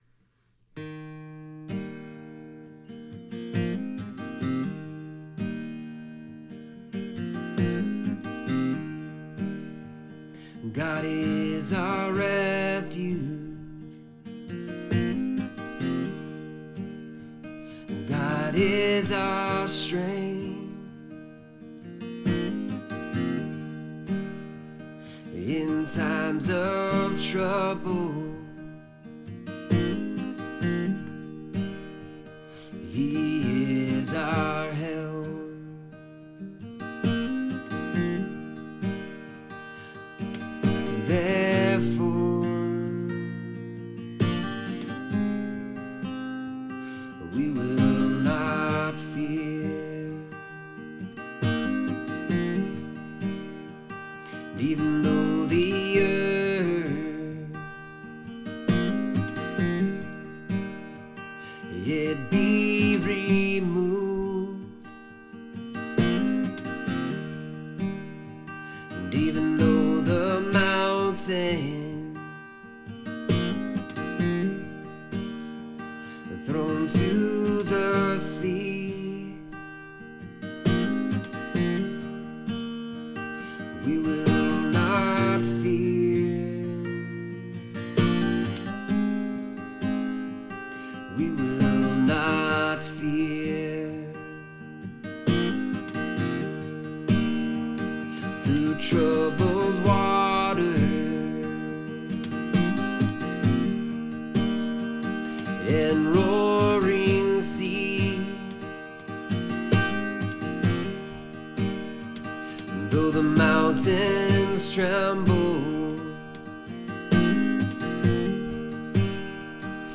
Here is where you can listen to some of the Psalms we have been putting to music.